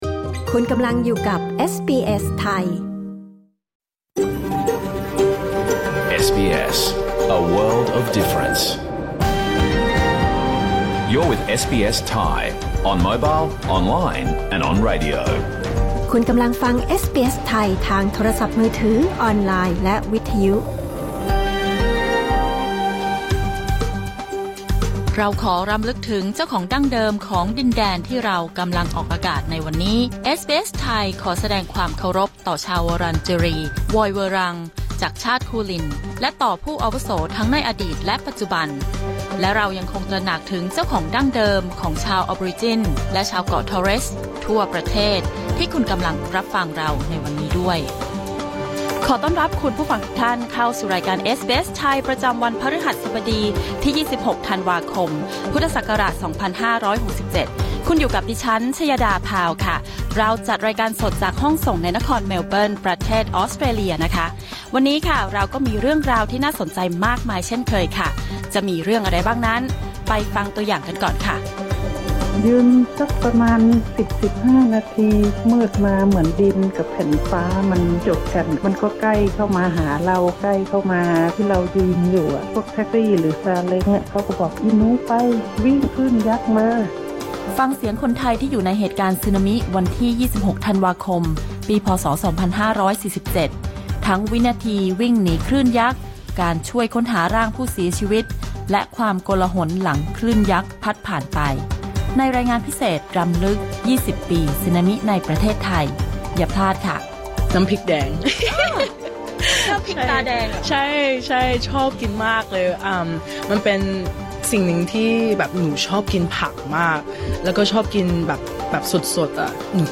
รายการสด 26 ธันวาคม 2567